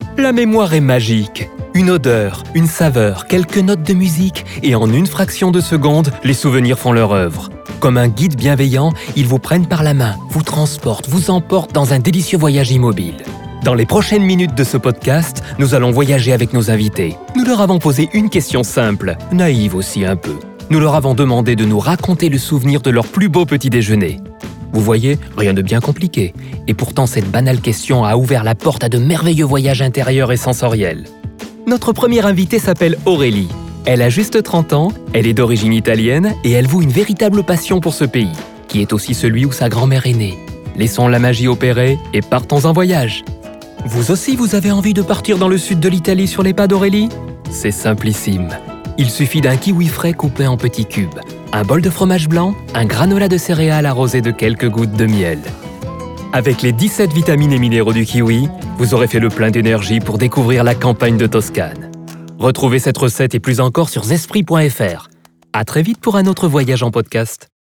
ZESPRI pub
Comédien